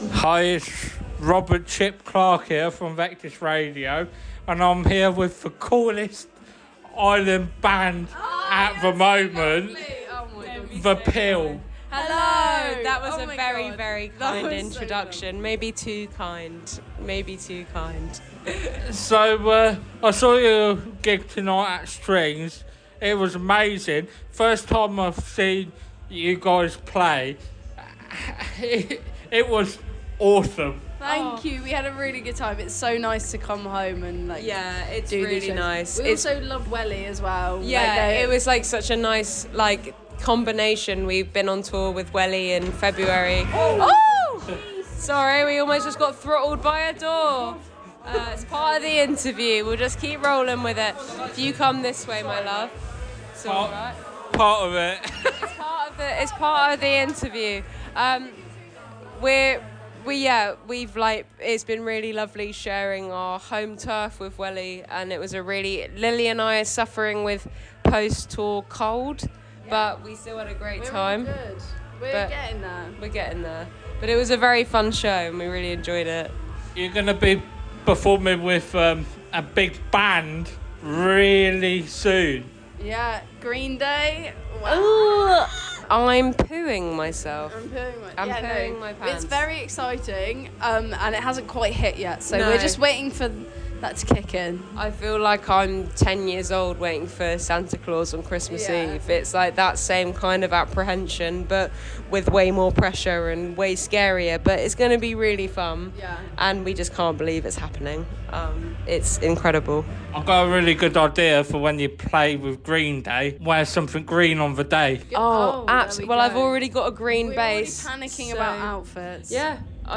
The Pill Strings Interview 2025.